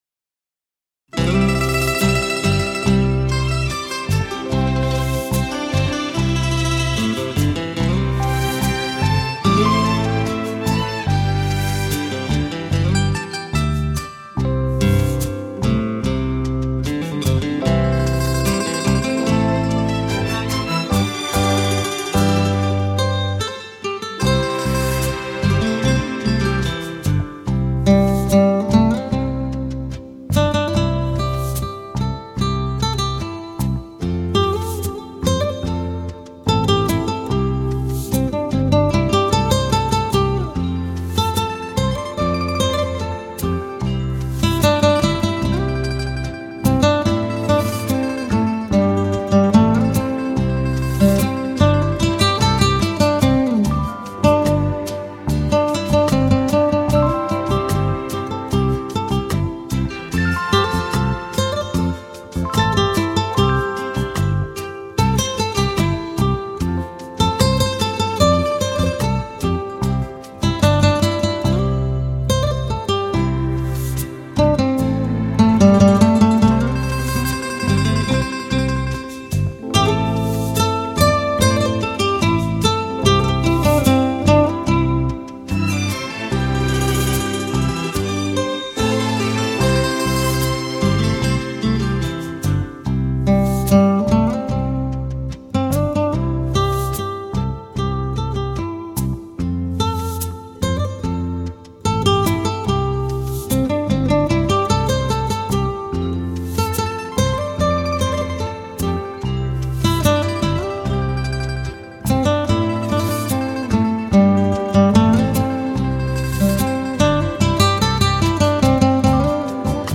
ギター
弹奏风格晶莹通透、飘曳轻灵，绝不炫技而是恰如其分，风格淳和不惊，真诚地将日本演歌独有迷人情调表现出来。